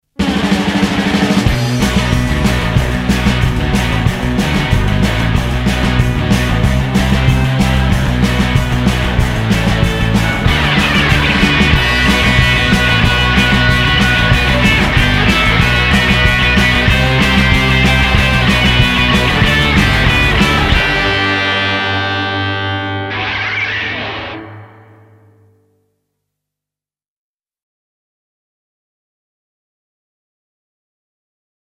punk version
Me on guitar
bass
drums